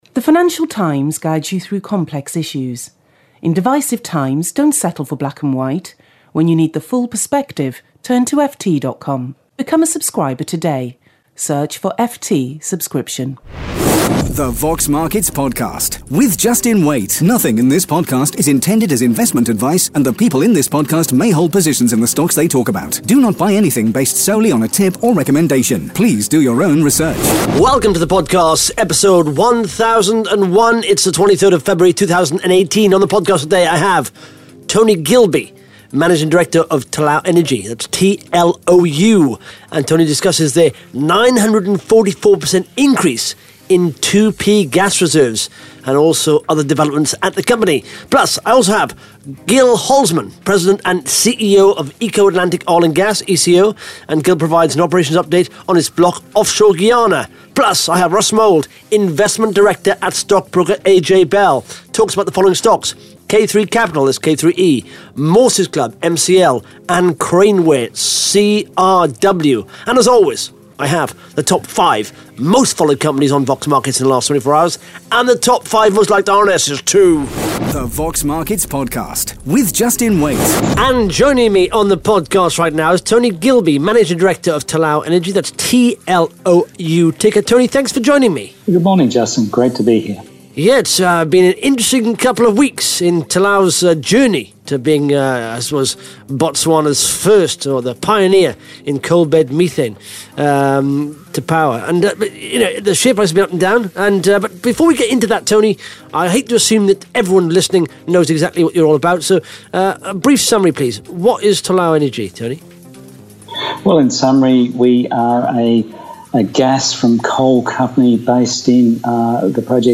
(Interview starts at 1 minute 17 seconds)